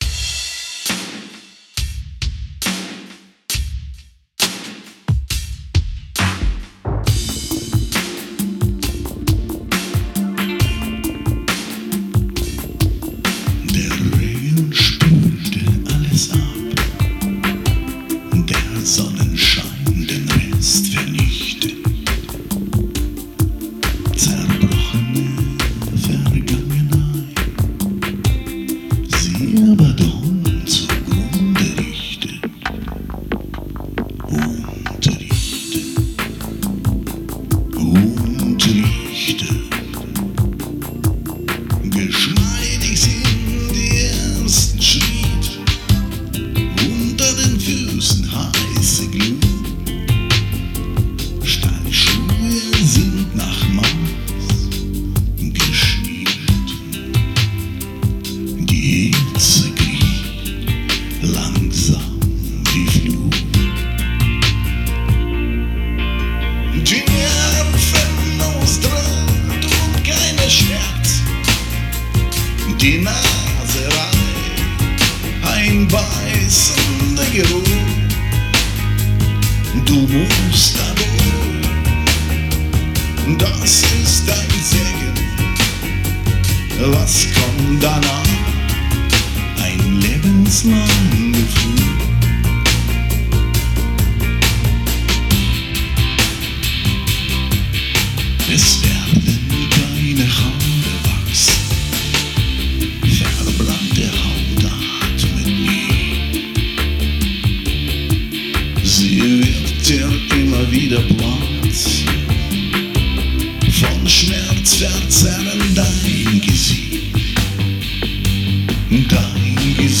(popschutz bei dieser Gesangsaufnahme war nicht im Spiel. Für Proben benutze ich ein Karaoke Mikrofon))) (ein Paar Patzer im Text sind auch vorhanden! bitte erstmal ignorieren))) Der Regen spülte alles ab der Sonnenschein den Rest vernichtet zerbrochene Vergangenheit, sie Abaddon zugrunde richtet ...